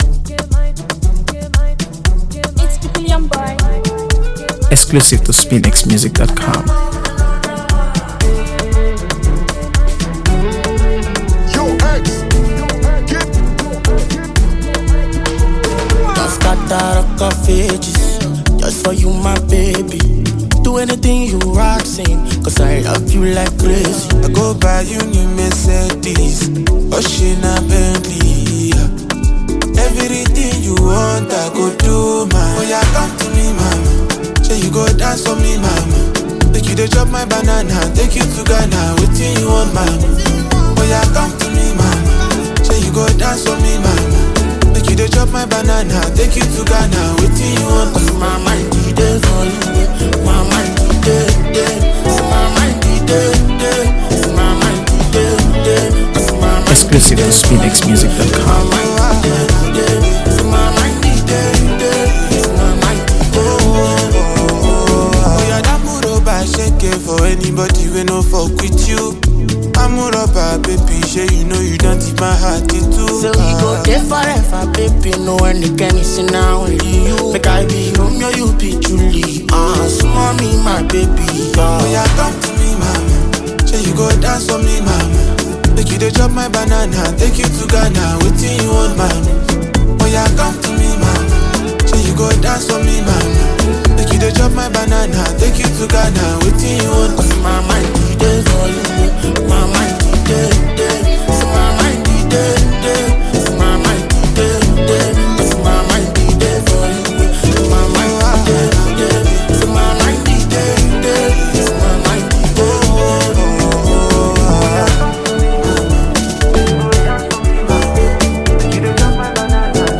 AfroBeats | AfroBeats songs
clean production and addictive rhythm